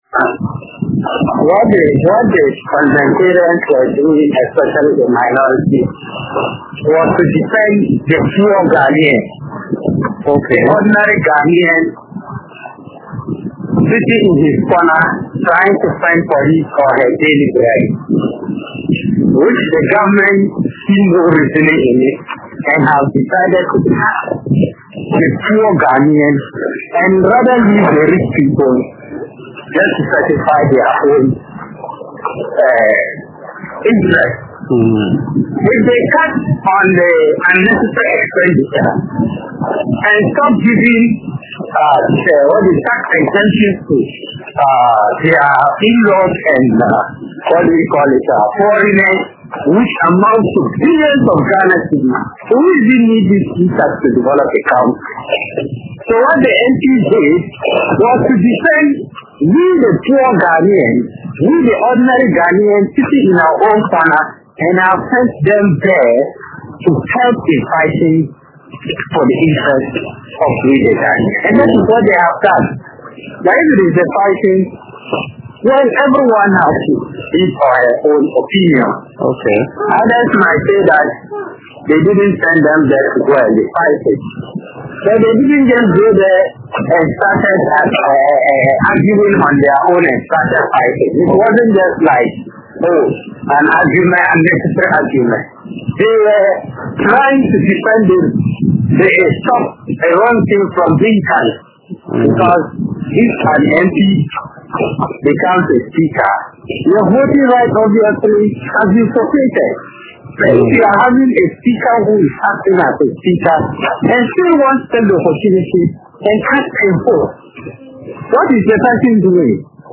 Below are some of the voices from residents: